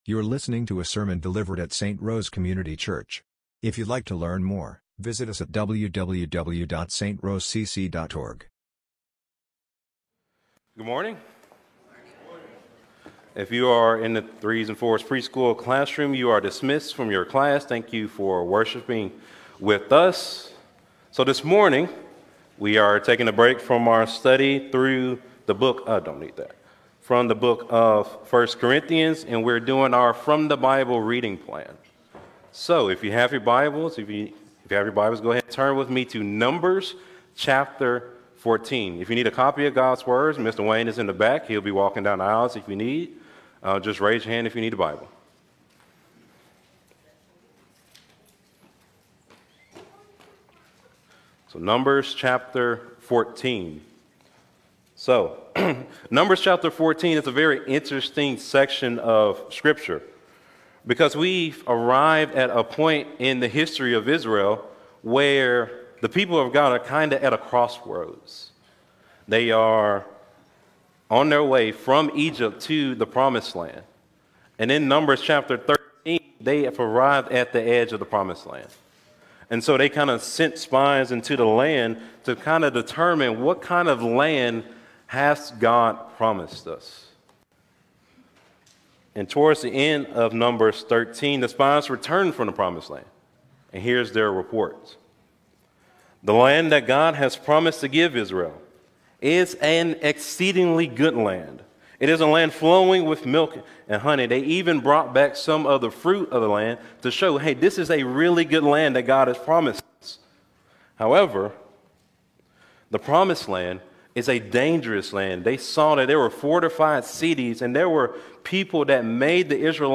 Sermons | St. Rose Community Church